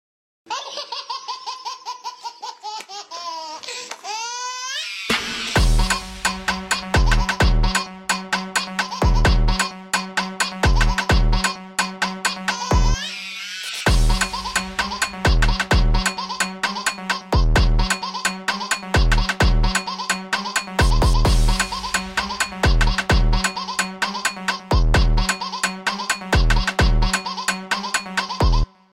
Baby Laughing Funk